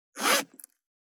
430,ジッパー,チャックの音,洋服関係音,ジー,バリバリ,カチャ,ガチャ,シュッ,パチン,ギィ,カリ,
ジッパー